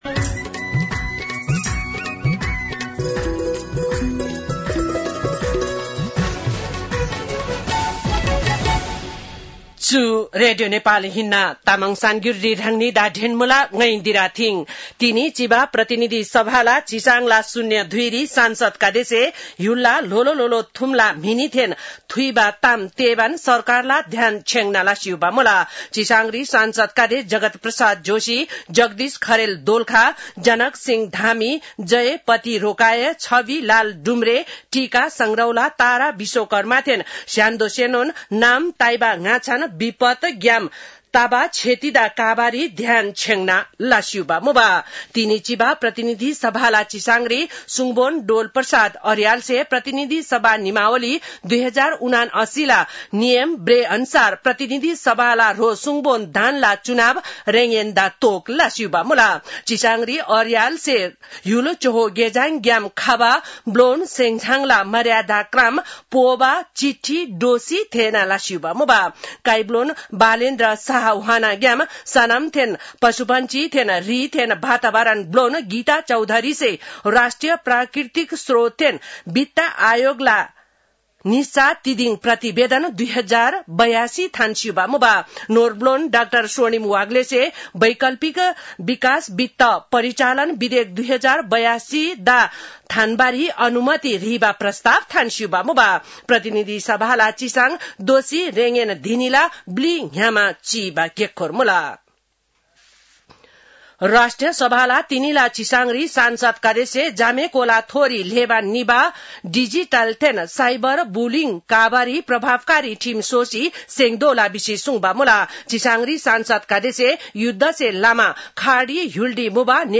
तामाङ भाषाको समाचार : २५ चैत , २०८२